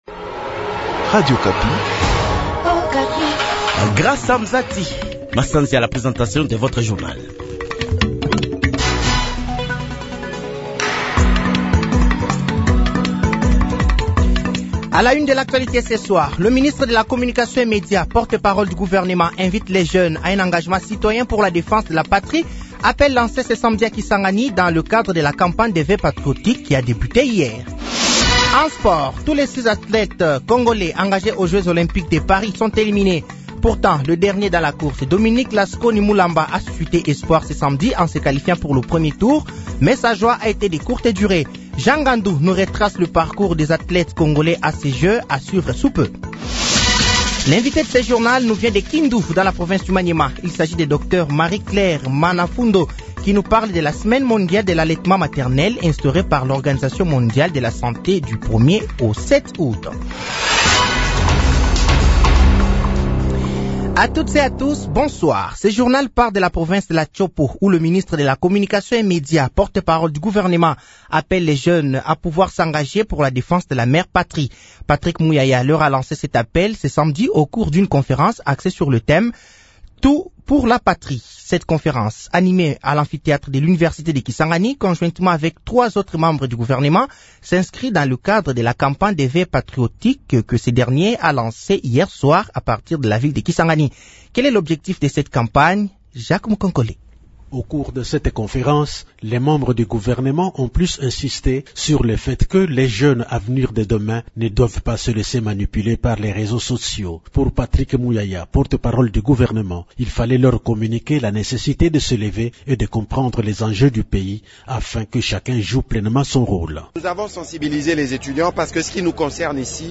Journal français de 18h de ce samedi 03 août 2024